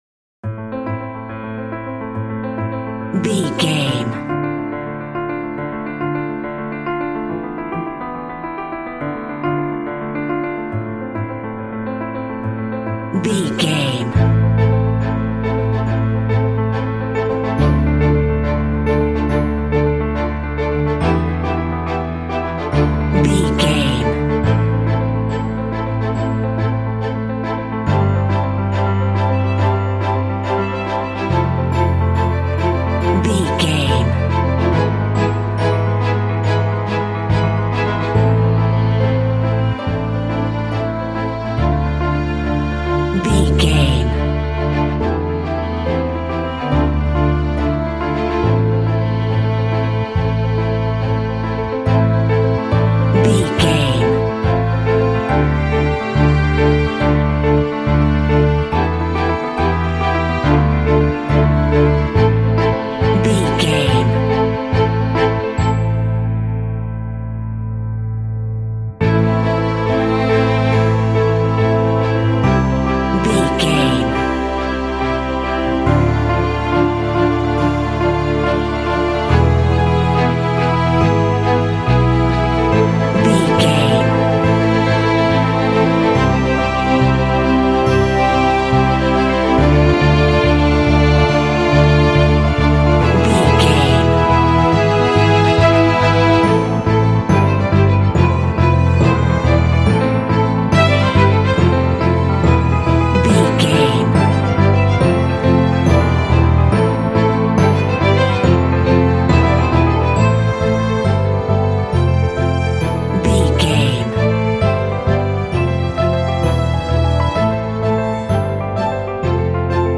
Aeolian/Minor
Fast
strings
film score
classical guitar